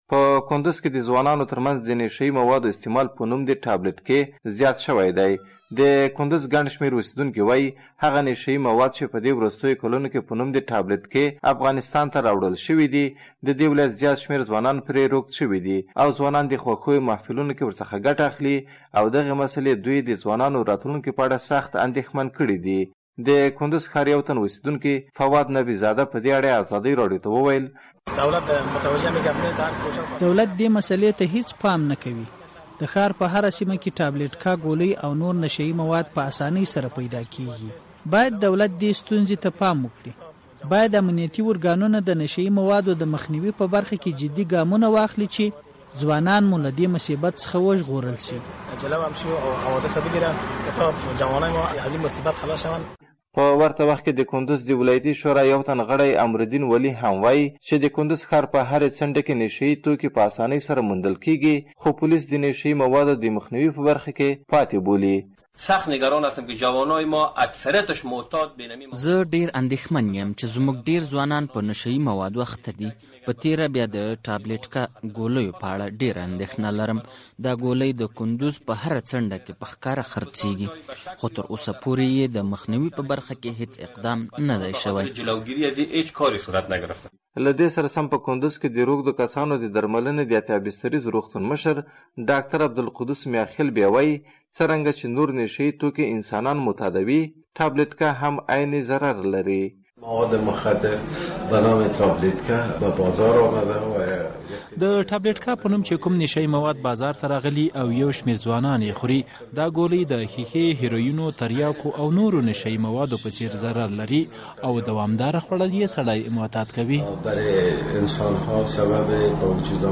راپور
د کندز راپور